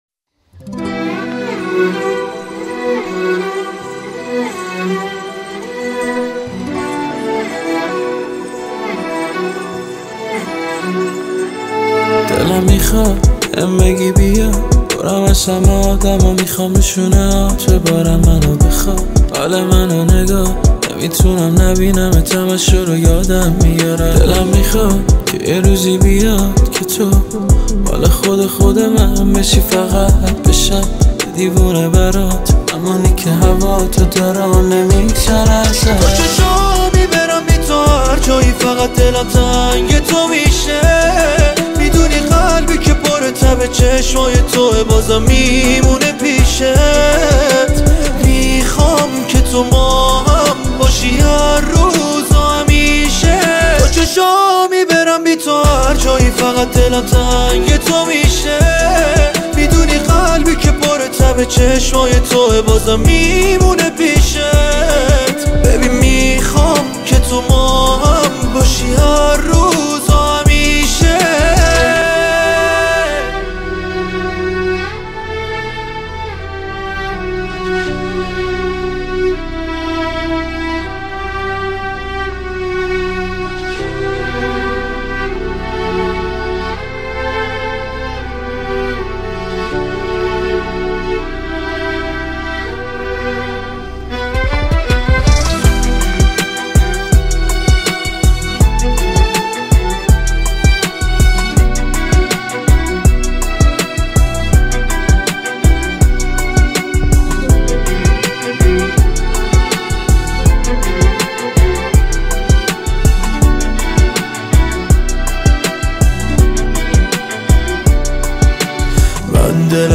گروه موسیقی پاپ